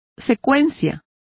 Complete with pronunciation of the translation of sequence.